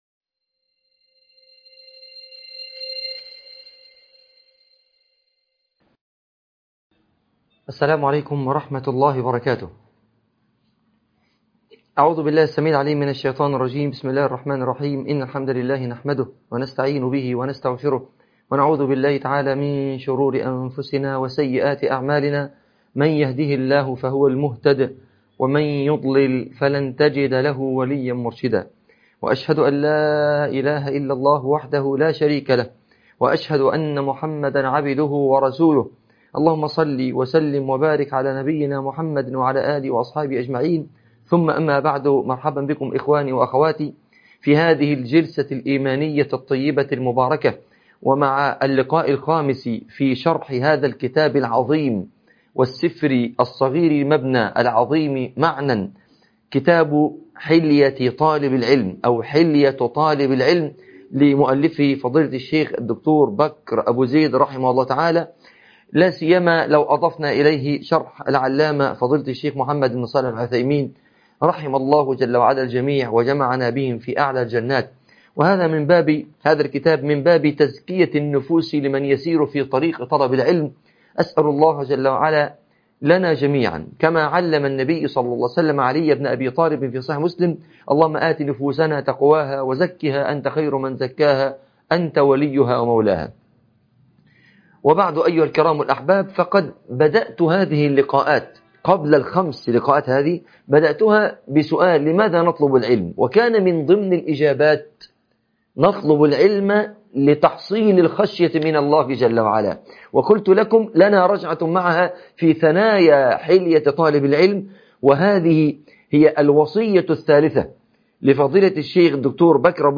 الخشية والمراقبة || محاضرة